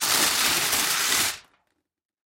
Огромный лист фольги скомкали в шар